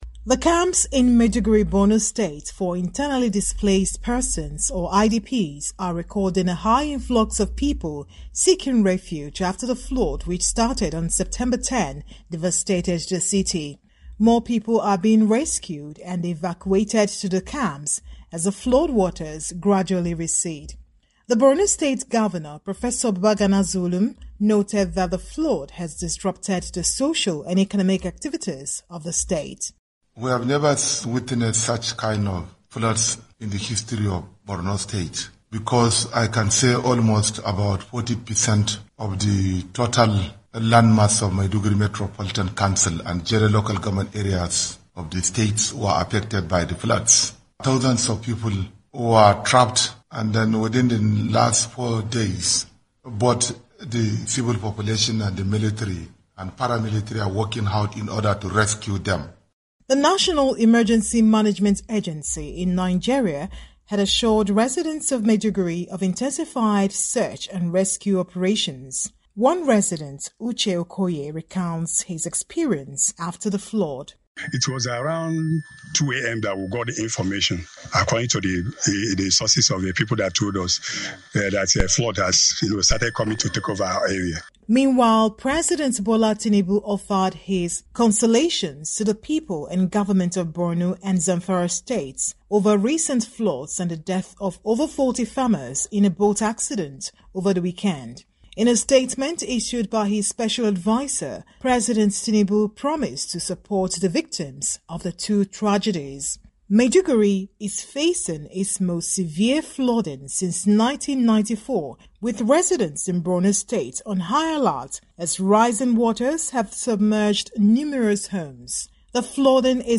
So far, about 29 camps have been erected across the state. Our reporter in Abuja gives us an update on the flood situation